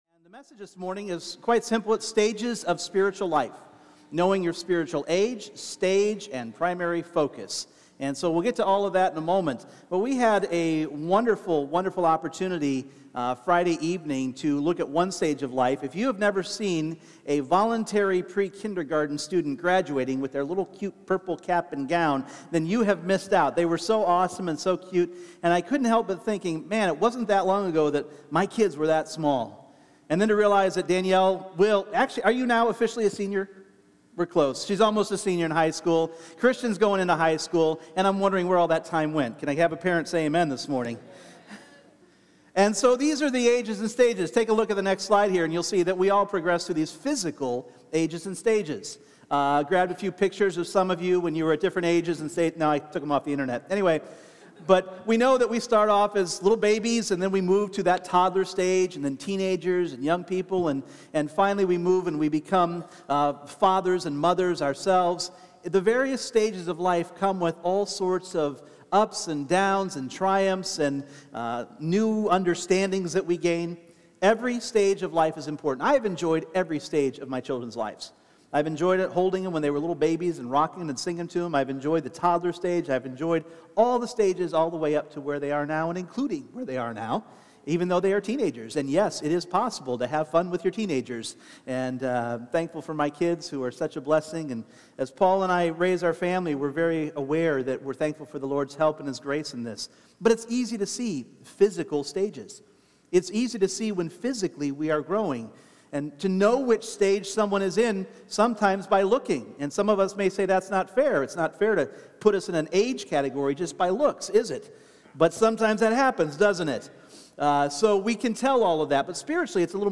Individual Messages Service Type: Sunday Morning What characteristics define each stage of our spiritual growth?